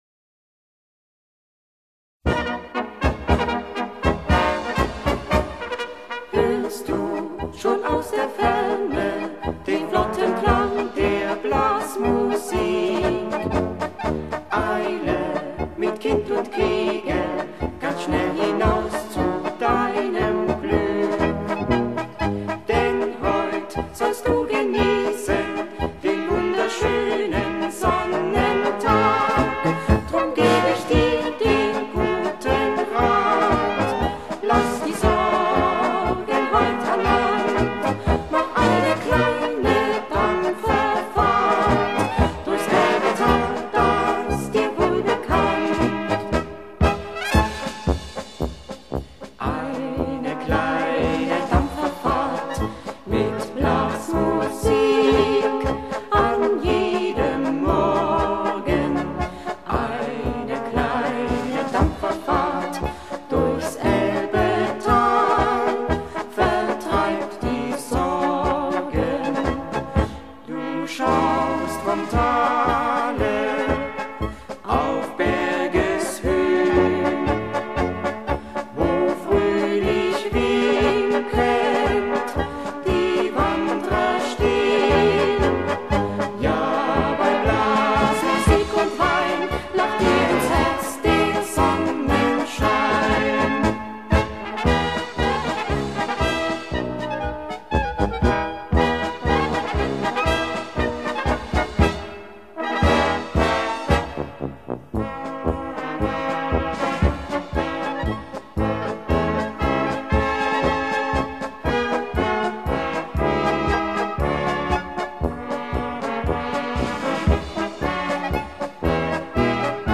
Polka mit Gesang